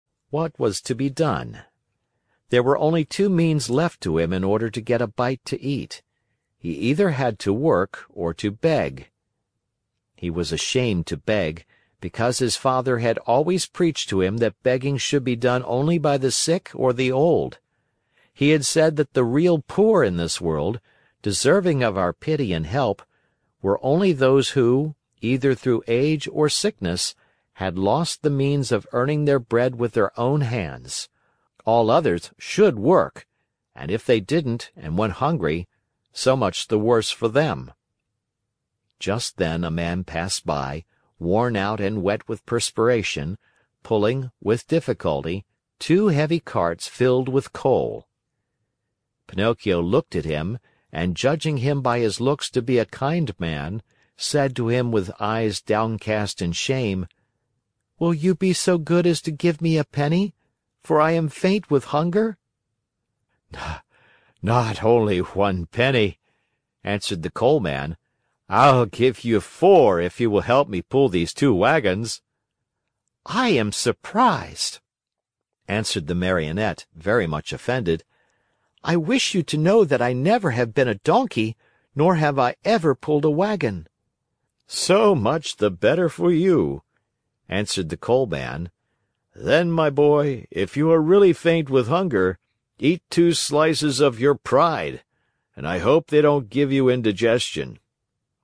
在线英语听力室木偶奇遇记 第77期:匹诺曹救父亲(4)的听力文件下载,《木偶奇遇记》是双语童话故事的有声读物，包含中英字幕以及英语听力MP3,是听故事学英语的极好素材。